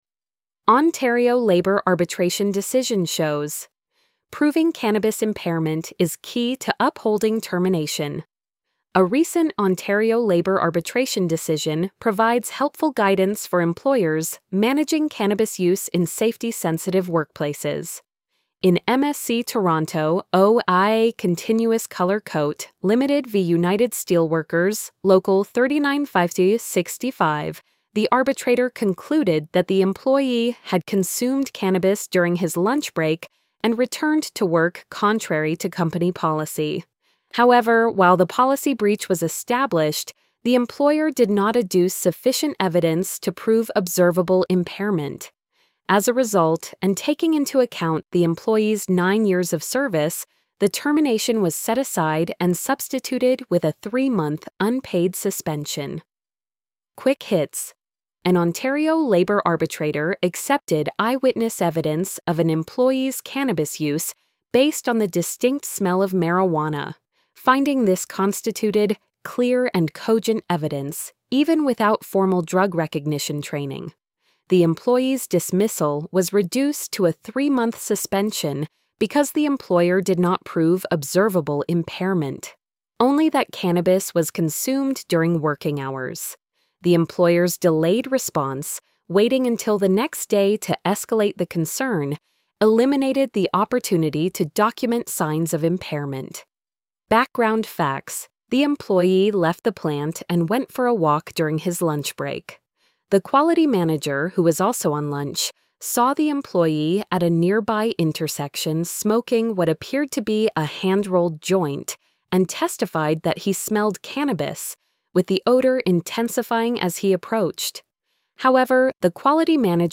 post-85991-tts.mp3